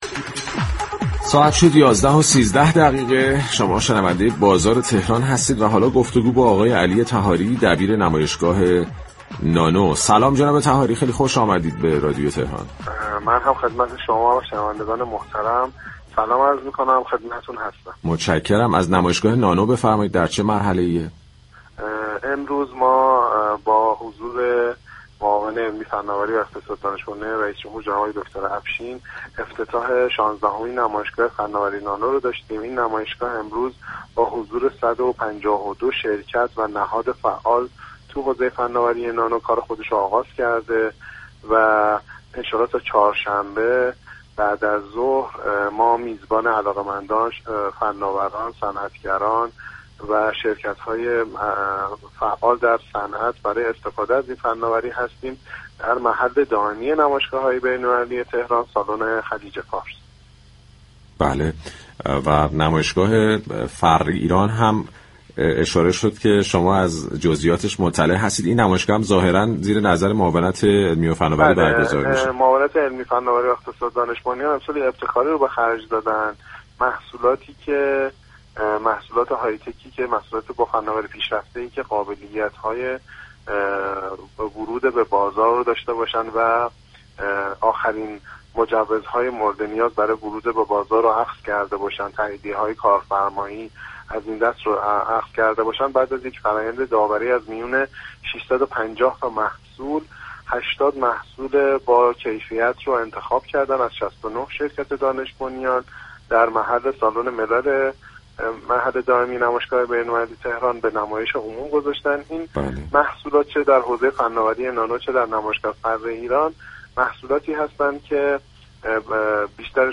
در گفت‌وگو با برنامه «بازار تهران» رادیو تهران